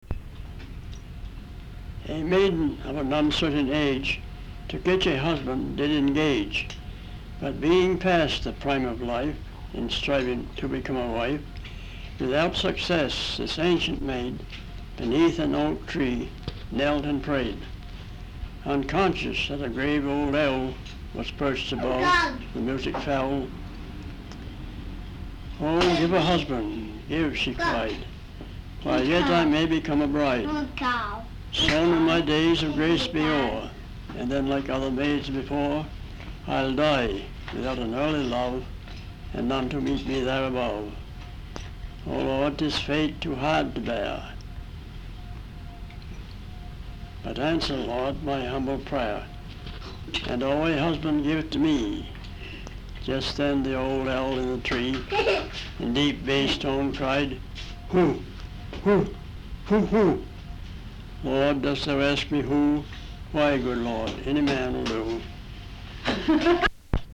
Folk songs, English--Vermont (LCSH)
sound tape reel (analog)
Location Guilford, Vermont